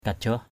/ka-ʥɔh/ (t.) hơn nữa nyu takrâ bac glaong kajaoh v~% tk;% bC _g*” k_j<H nó muốn học cao hơn nữa.